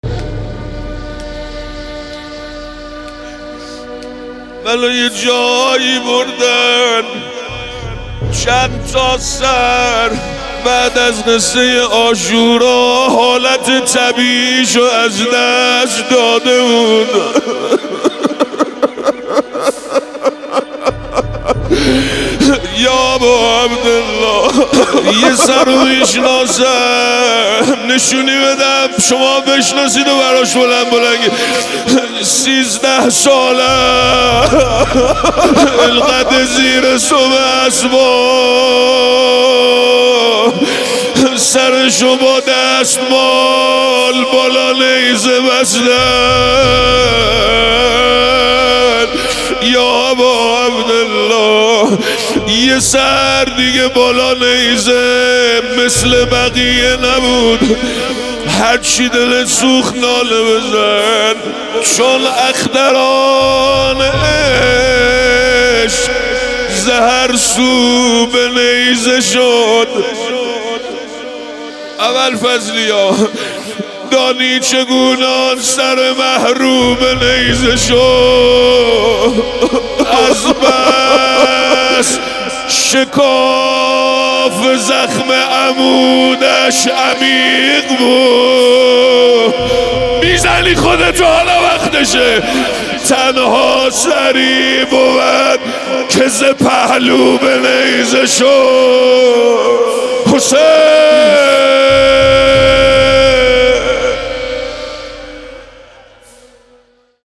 روضه سر مه رو به نیزه شد
ایام فاطمیه 1399